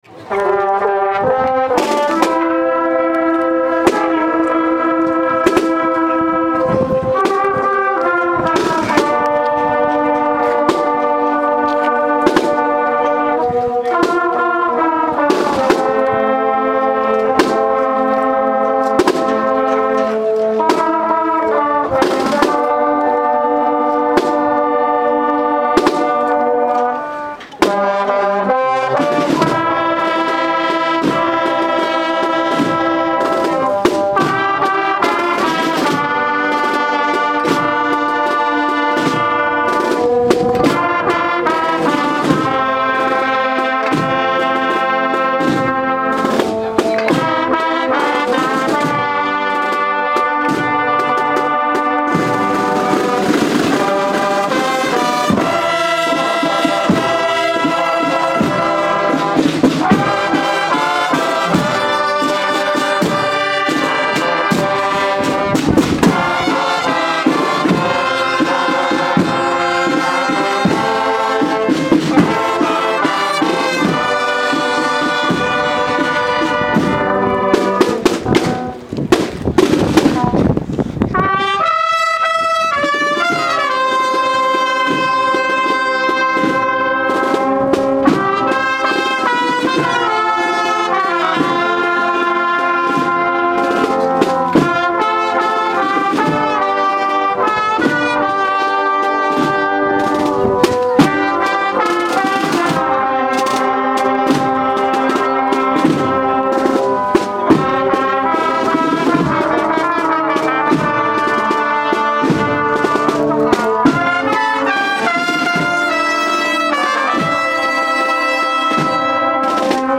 Procesión de Lébor 2013
Participó de la banda de cornetas y tambores de la Cofradía del Santísimo Cristo de la Caída.